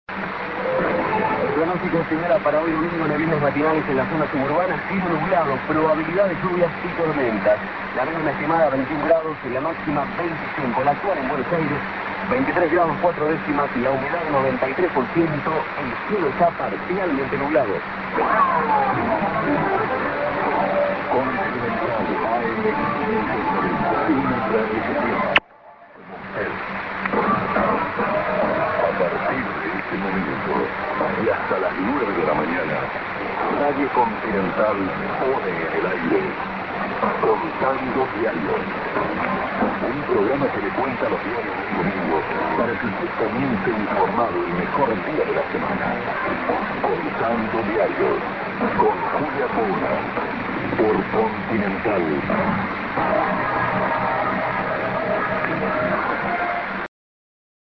prog->00'32":ID(man:RadioContinental )->prog